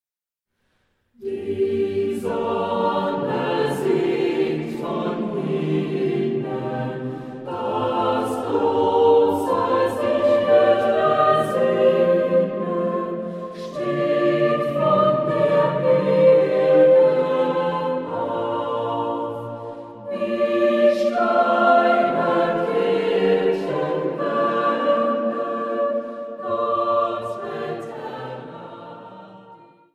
Truhenorgel